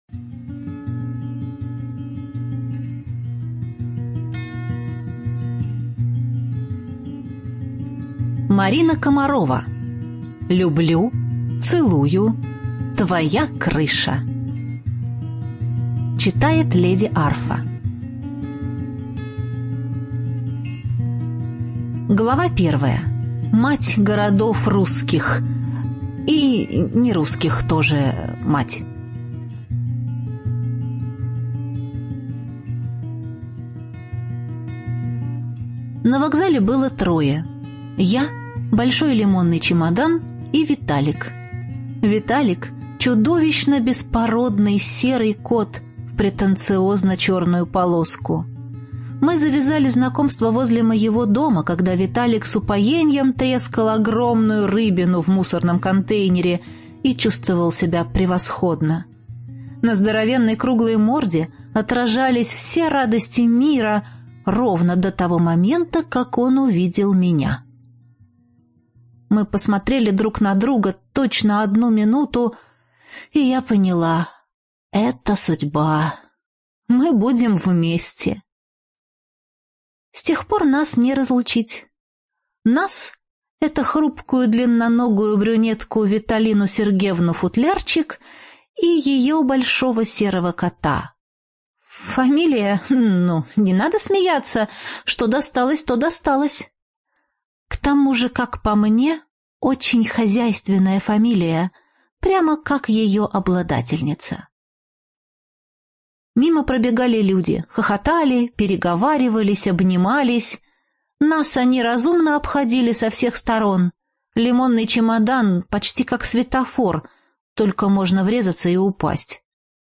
Жанр: Современный сентиментальный роман